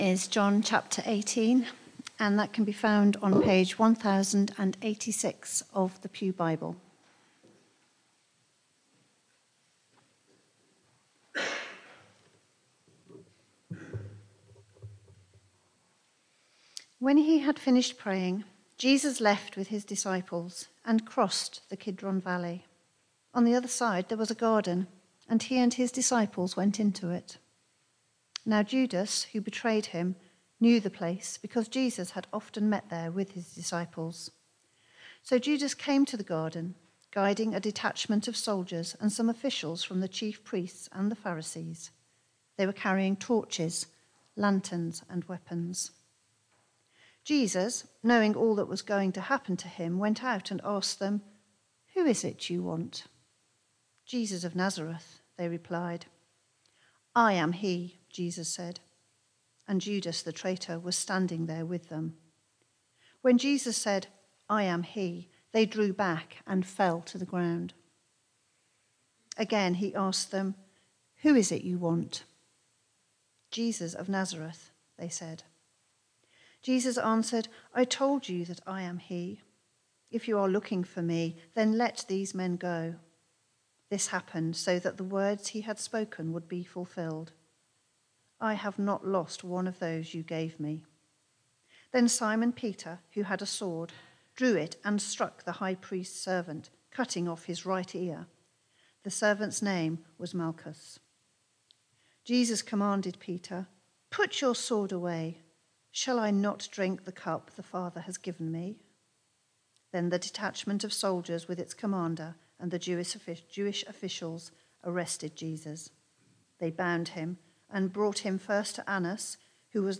Media for Barkham Morning Service on Sun 06th Apr 2025 10:00
Theme: Sermon